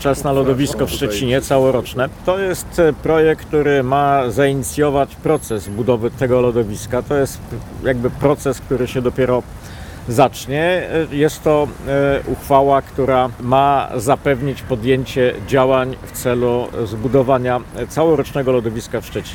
Jednym z inicjatorów projektu jest radny Maciej Kopeć, który podkreśla, że to dopiero początek, ale bardzo ważny: